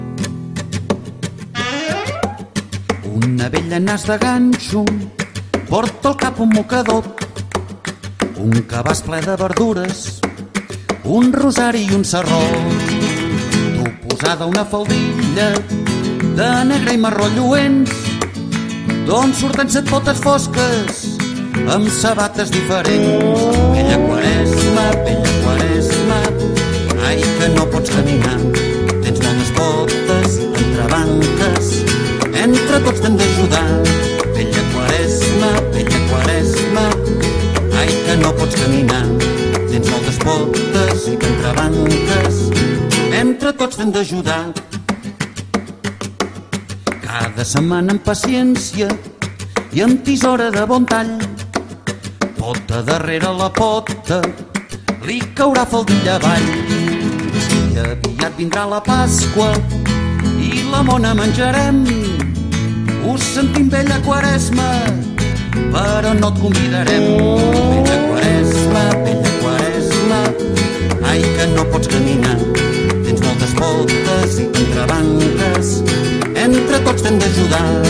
Cada divendres, tots els nens i nenes de parvulari ens trobem al vestíbul per treure-li un mitjó i cantar-li la seva cançó.